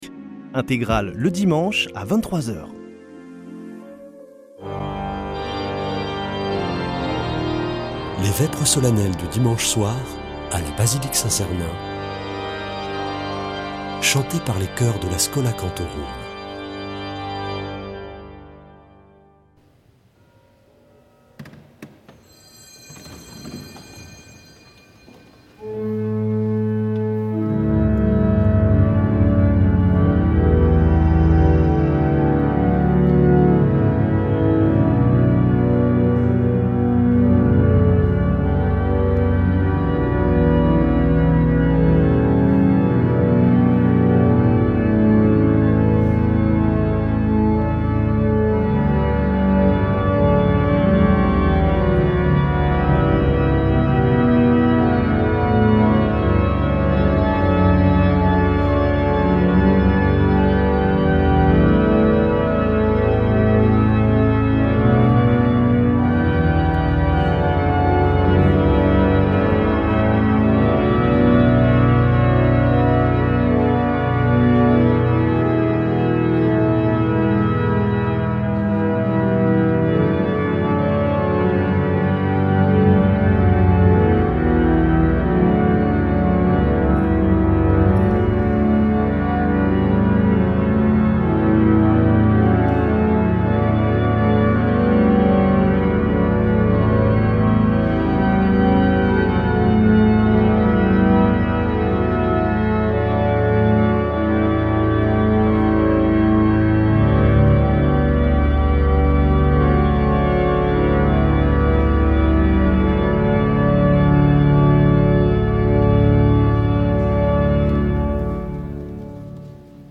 Schola Saint Sernin Chanteurs